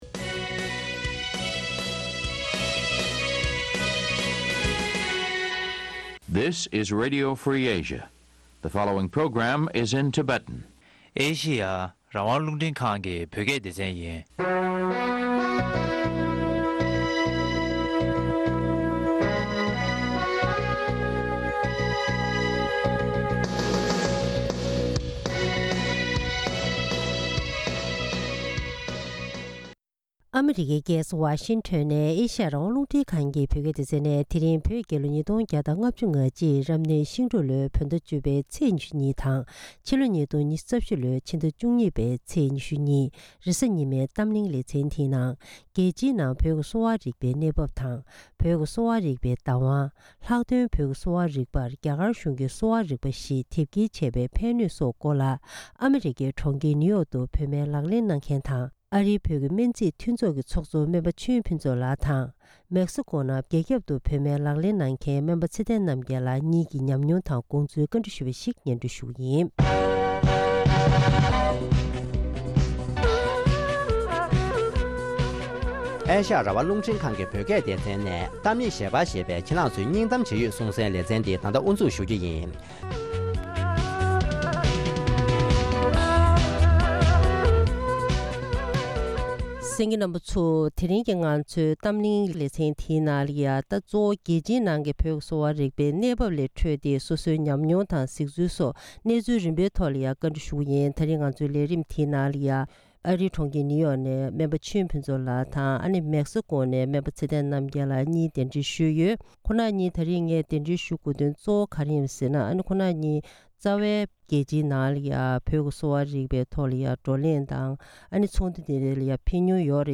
ཐེངས་འདིའི་གཏམ་གླེང་གི་ལེ་ཚན་ནང་།